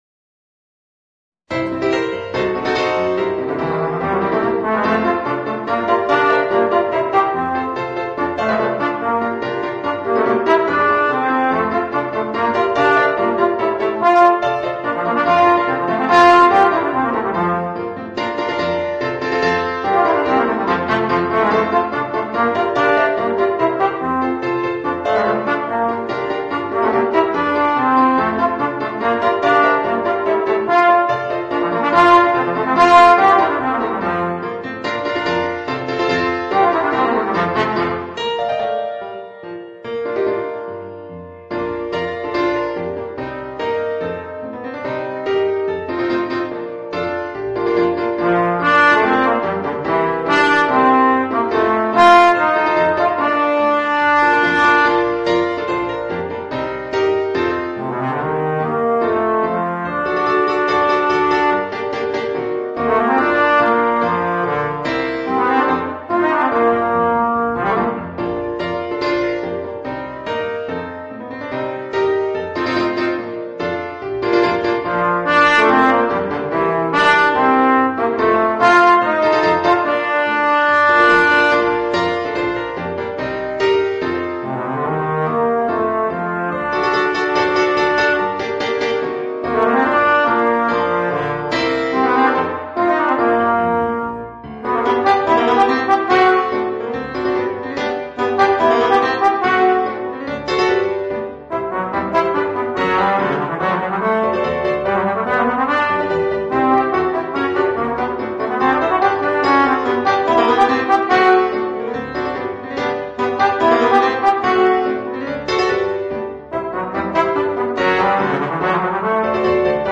Trombone & Piano